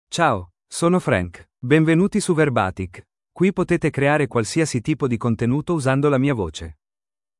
FrankMale Italian AI voice
Frank is a male AI voice for Italian (Italy).
Voice sample
Male